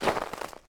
pedology_ice_pure_footstep.2.ogg